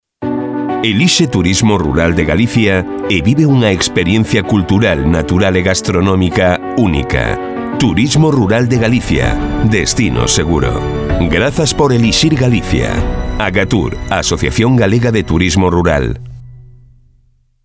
Voz directa para transmitir emociones
Sprechprobe: Sonstiges (Muttersprache):
Direct voice to convey emotions